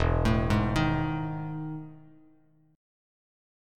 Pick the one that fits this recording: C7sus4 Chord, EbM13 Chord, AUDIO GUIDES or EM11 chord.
EM11 chord